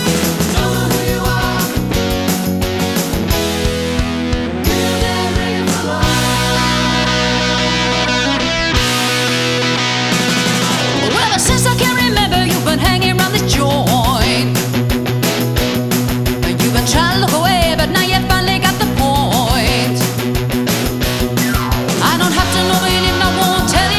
With Girl Rock 4:15 Buy £1.50